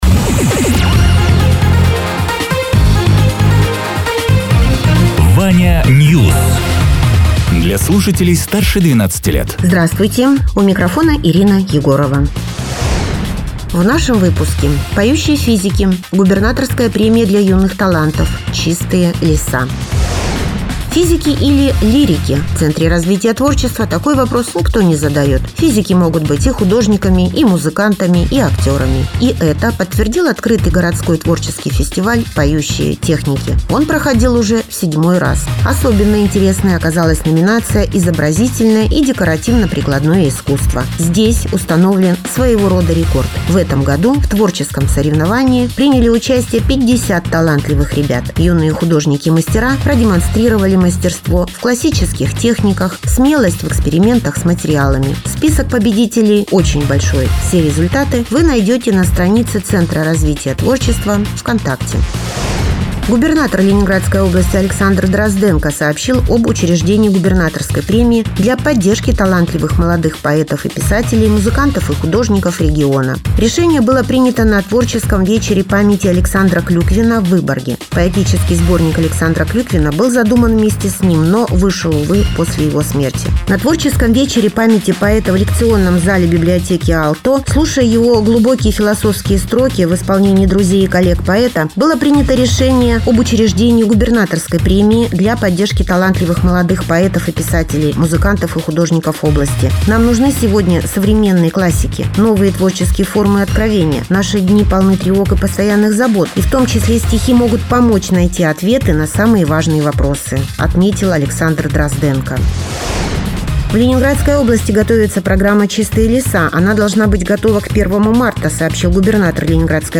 Радио ТЕРА 08.12.2025_10.00_Новости_Соснового_Бора